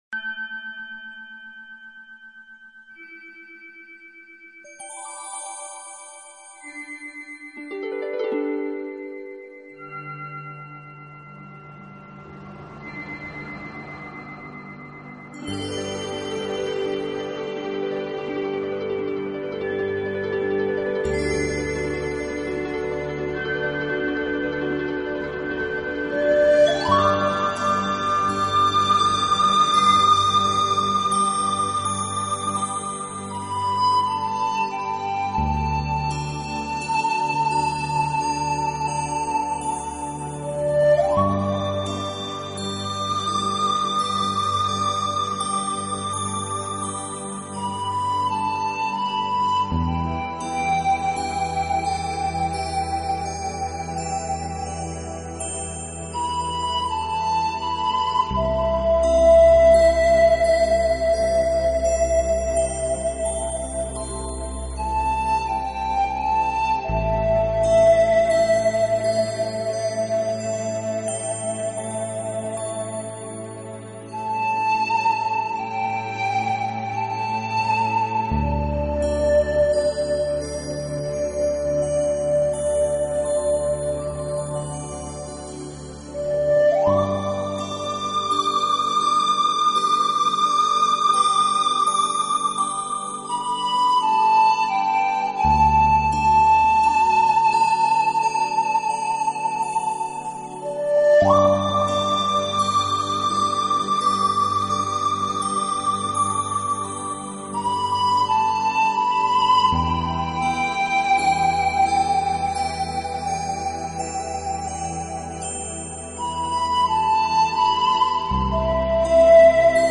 艺 术 家：  纯音乐
音乐流派：  Nature  Music